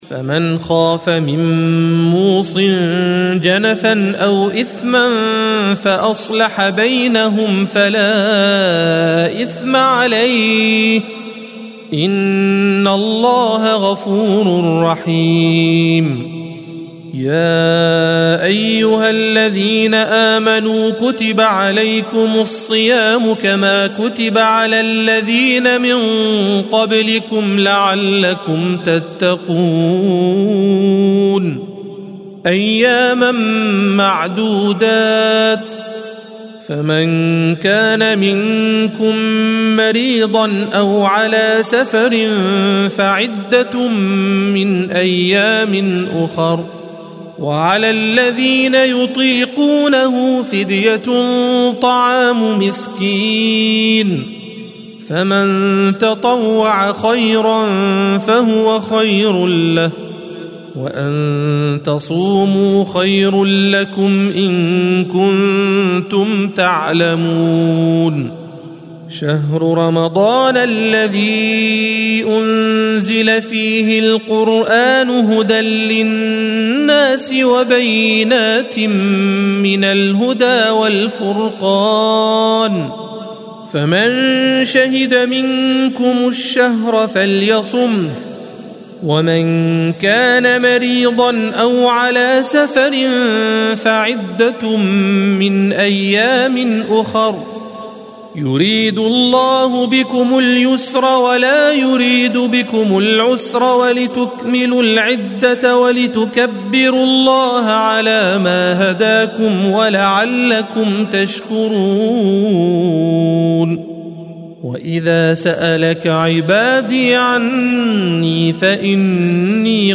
الصفحة 28 - القارئ